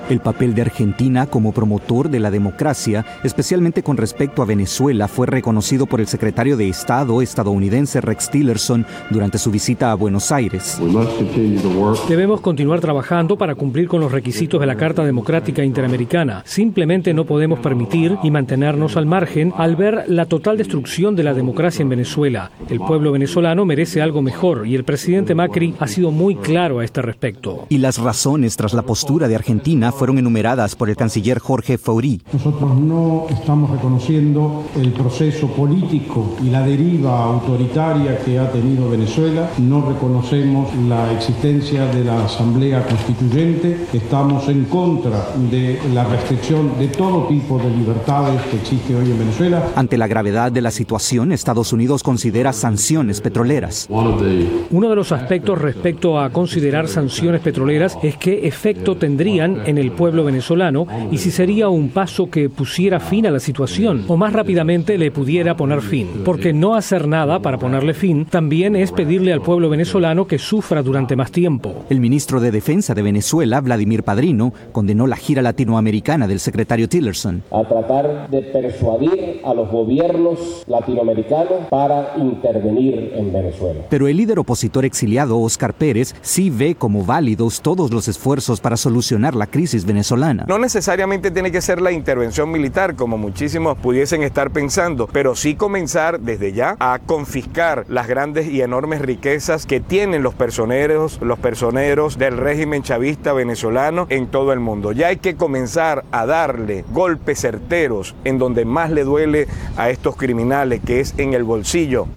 Declaraciones de Rex Tillerson en Argentina antes de partir a Lima